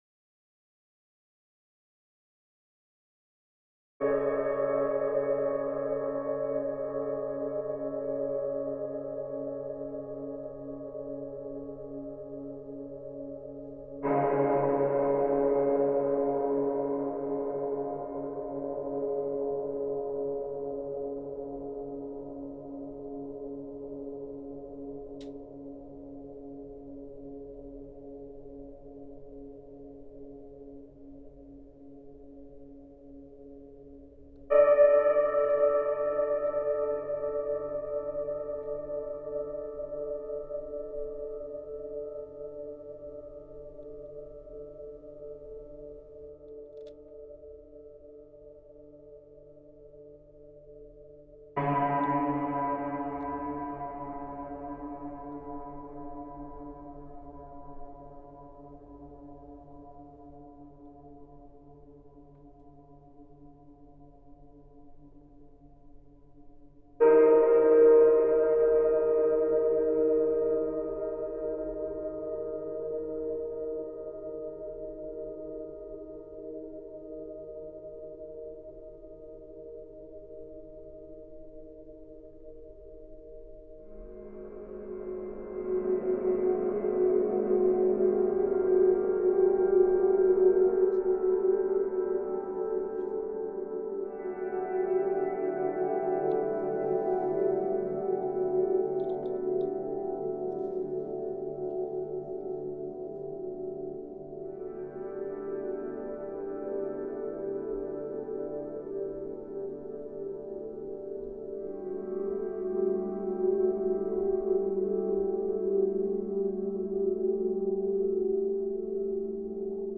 In Sympathy Music for CymaPhone A portfolio of sympathetic resonance compositions enlightened by the mystical forms of rāga and sound healing.